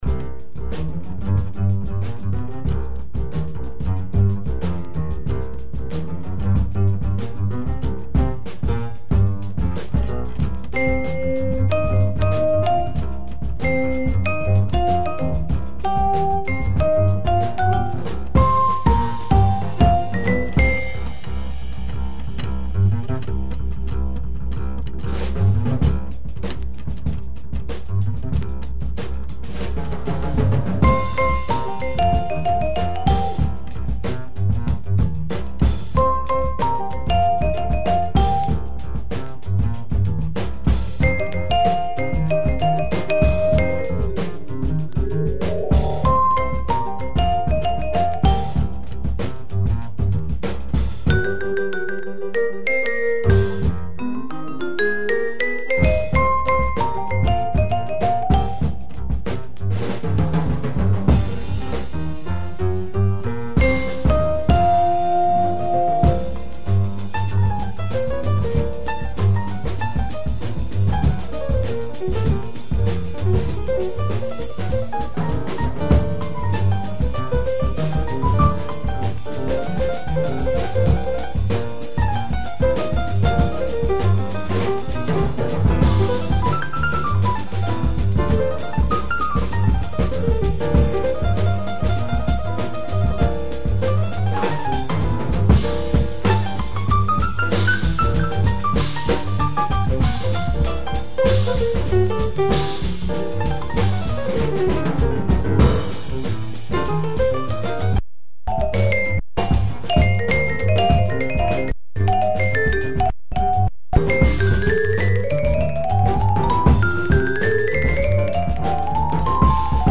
groovy jazz tunes
Jazz